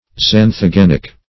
Search Result for " xanthogenic" : The Collaborative International Dictionary of English v.0.48: Xanthogenic \Xan`tho*gen"ic\, a. [See Xantho- , and -gen .]